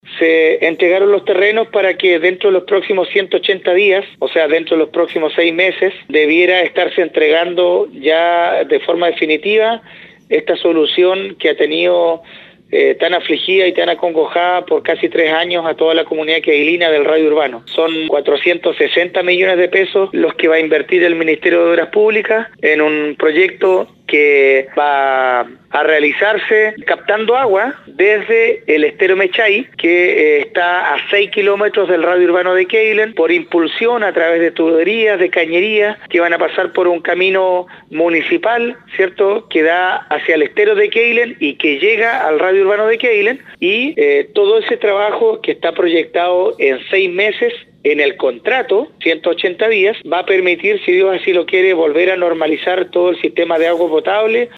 El alcalde Marcos Vargas señaló al respecto que se hace imprescindible resguardar la salud de la población y por ello se llevó adelante esta iniciativa.
05-ALCALDE-QUEILEN.mp3